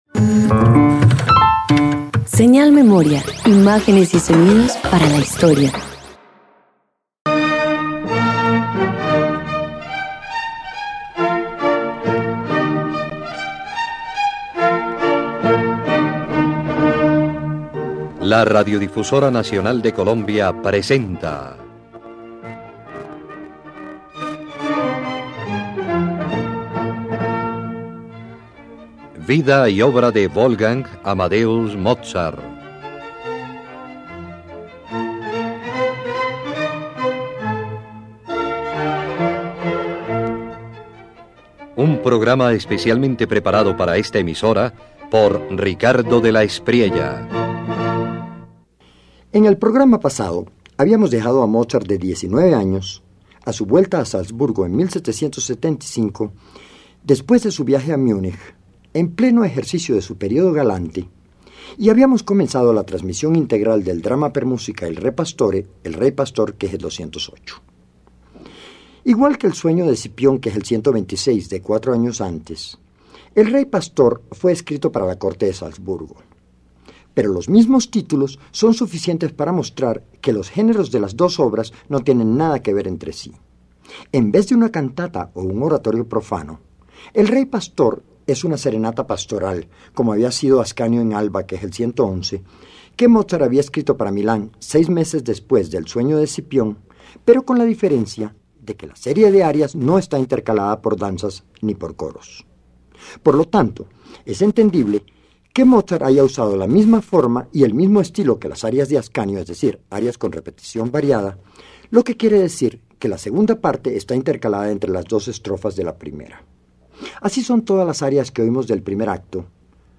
En el segundo acto de El rey pastor, Amintas asume el trono de Sidón y se aleja de Elisa, quien, herida por su indiferencia, expresa su dolor en el aria Bárbaro, o Dio. Mozart une pasión y virtuosismo en una melodía de fuerza deslumbrante.